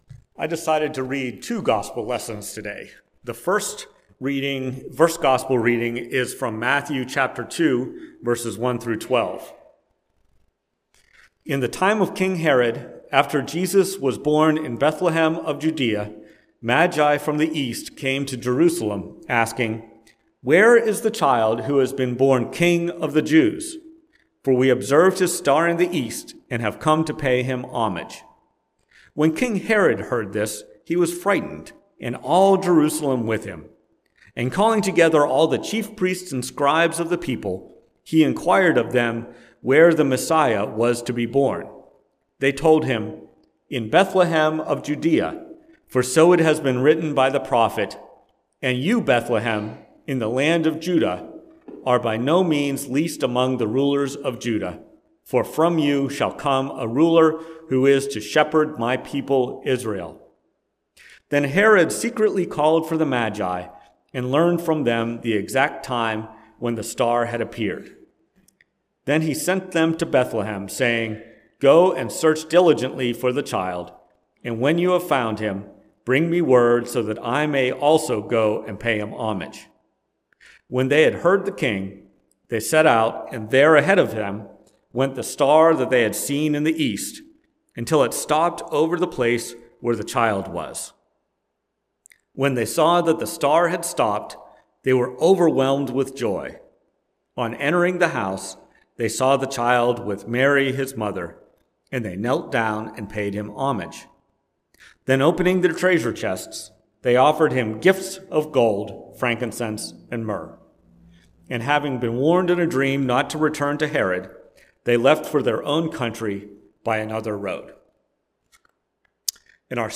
Preached at First Presbyterian Church of Rolla on January 7, 2024, which combined Epiphany and Baptism of the Lord. Based on Matthew 2:1-12 and Mark 1:4-11.